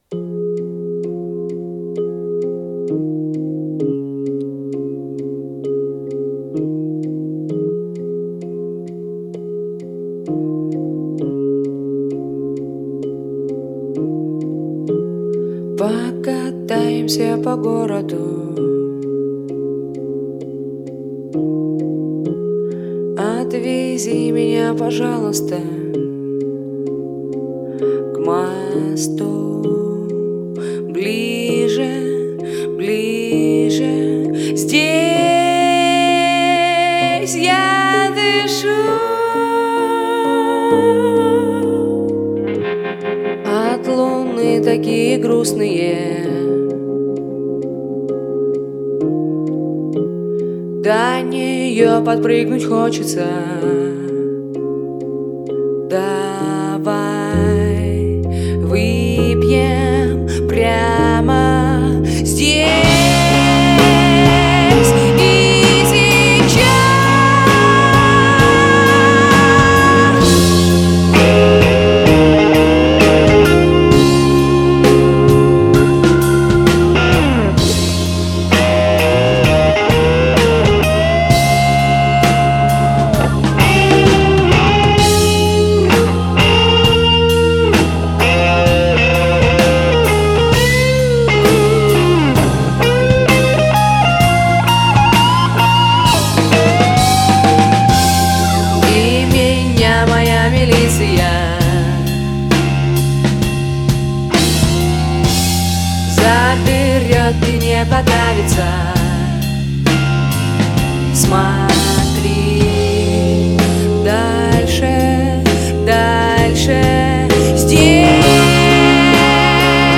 z_-_bliuz.mp3